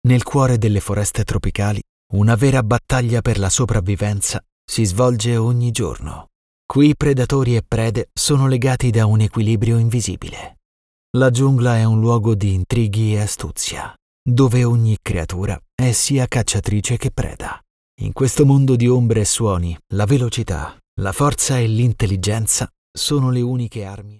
Male
Bright, Engaging, Friendly, Versatile, Authoritative, Character
Microphone: Neumann TLM 103, Universal Audio Sphere Dlx